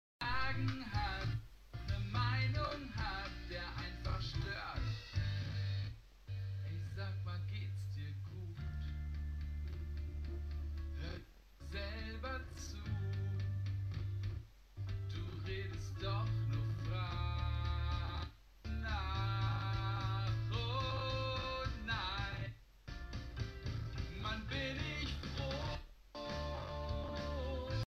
Audio Aussetzer, Bitcrushing, aufschaukeln von Knacksen